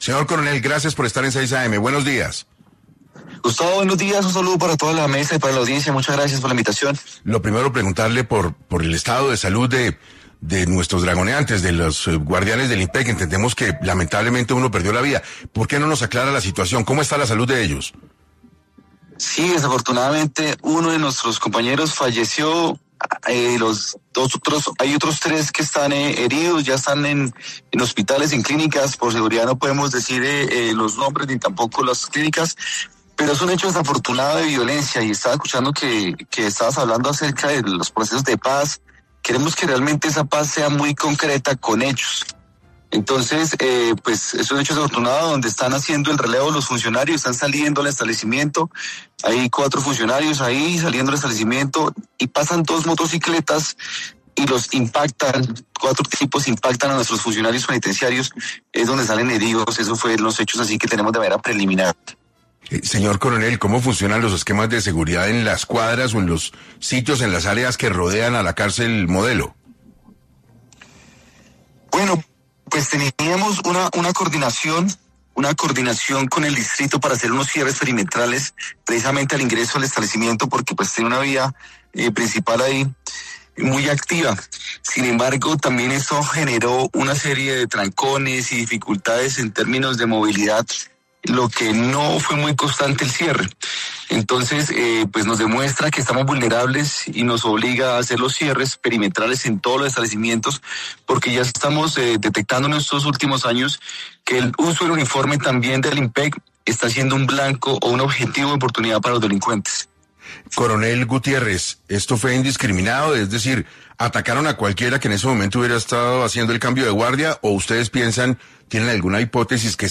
El coronel Daniel Gutiérrez, director del Instituto Nacional Penitenciario y Carcelario de Colombia (Inpec) habló en 6AM del atentado sicarial a tres funcionarios